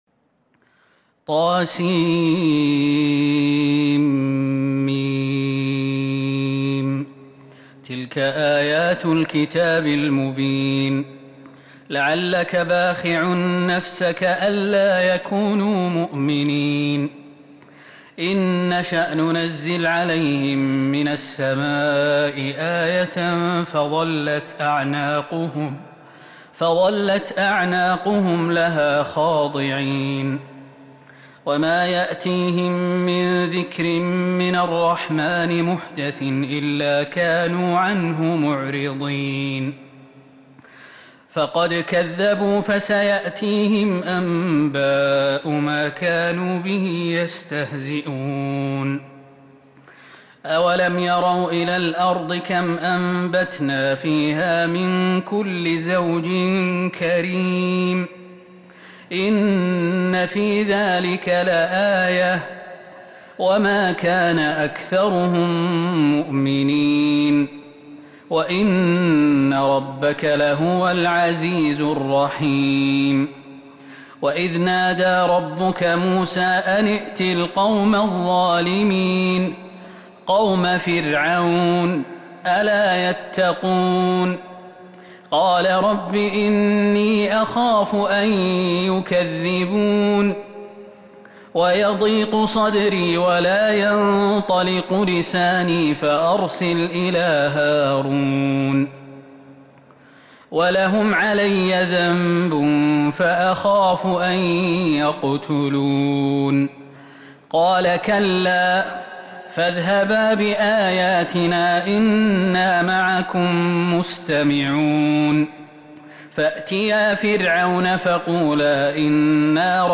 سورة الشعراء كاملة من تراويح الحرم النبوي 1442هـ > مصحف تراويح الحرم النبوي عام 1442هـ > المصحف - تلاوات الحرمين